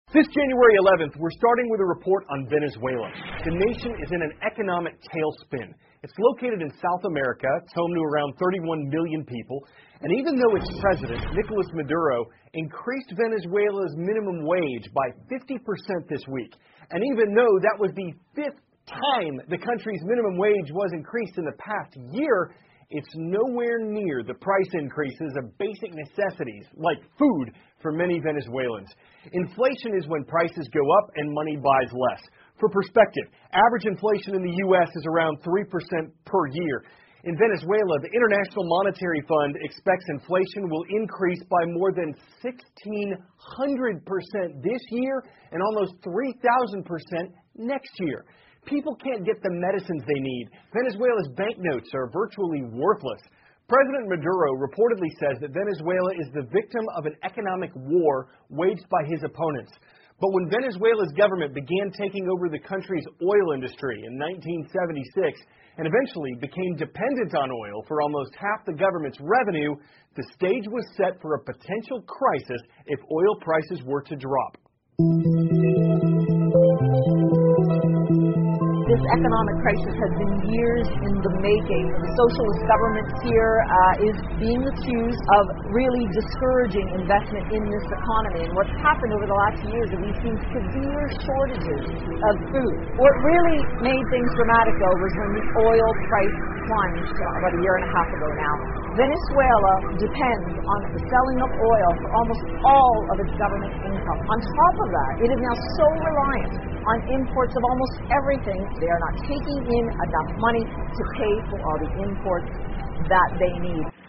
美国有线新闻 CNN 委内瑞拉经济失控 通胀率可能超过1600% 听力文件下载—在线英语听力室